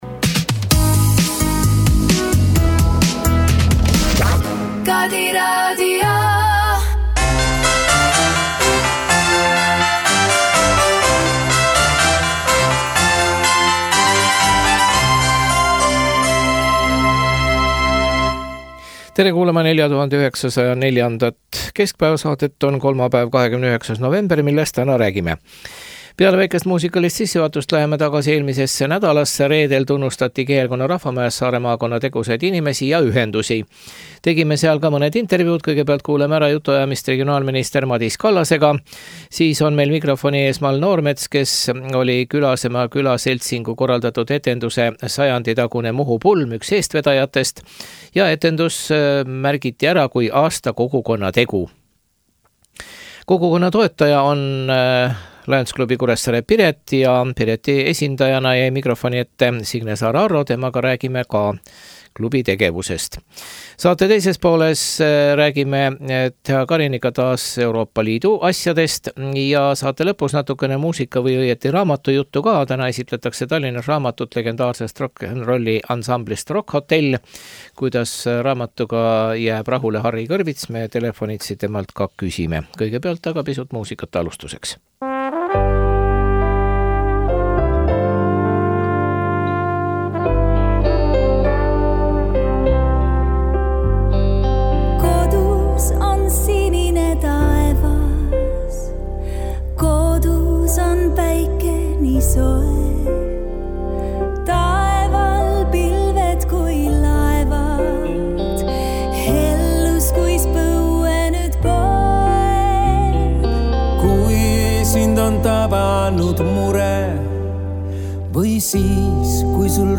Reedel tunnustati Kihelkonna rahvamajas Saare maakonna tegusaid inimesi ja ühendusi. Tegime intervjuu regionaalminister Madis Kallasega.